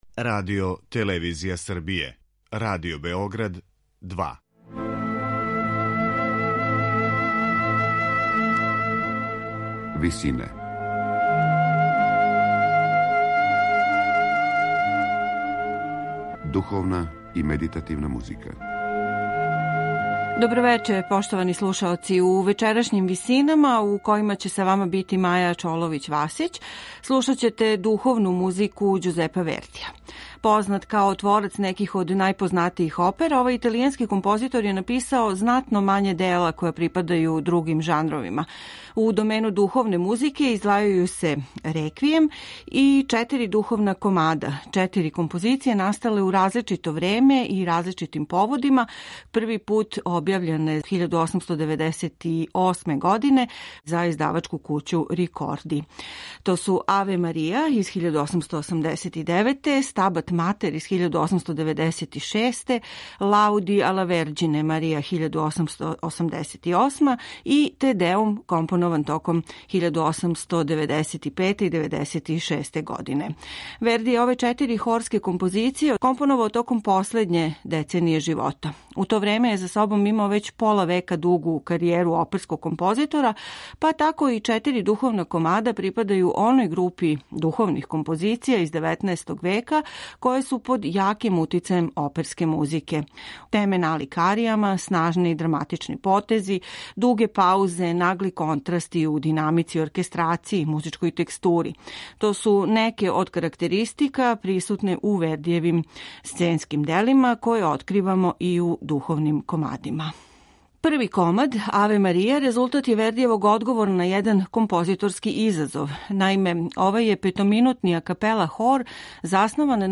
слушаћете снимак Филхармонија хора и орекстра којима диригује Карло Мариа Ђулини. Солисткиња је Џенет Бејкер.